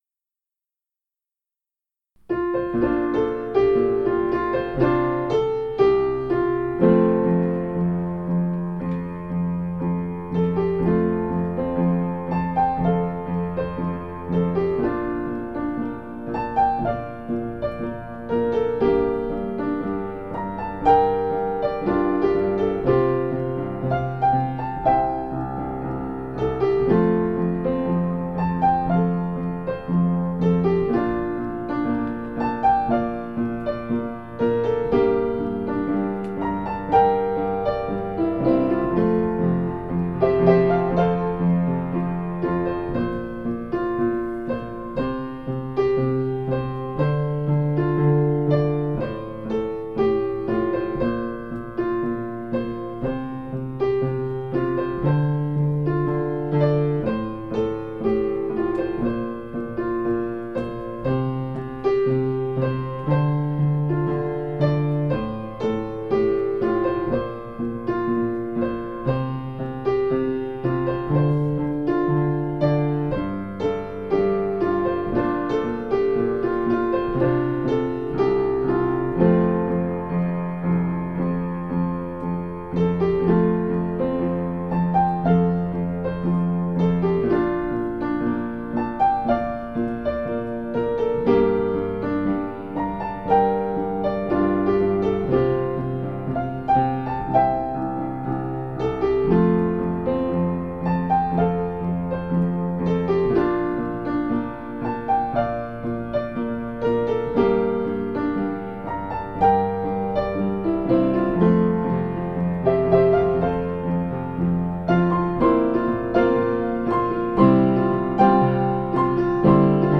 We also posted audio of the entire worship, including the sermon.